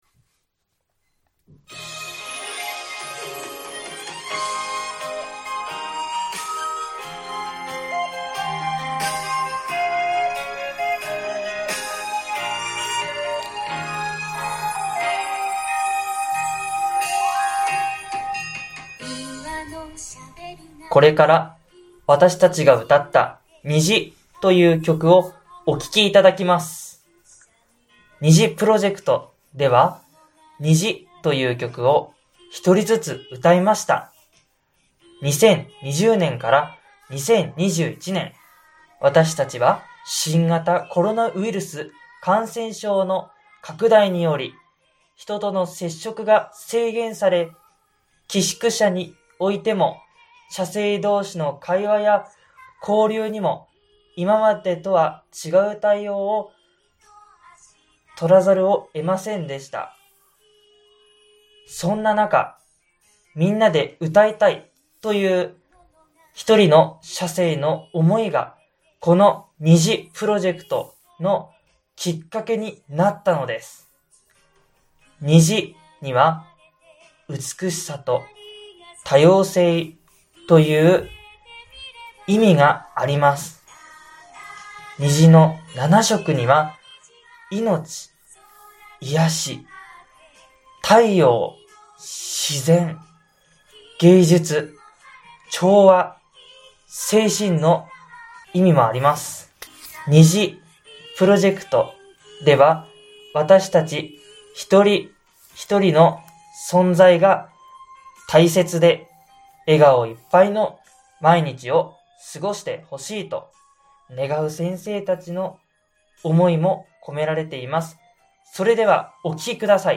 ※プロジェクトの説明と曲を合わせて５分１５秒あります。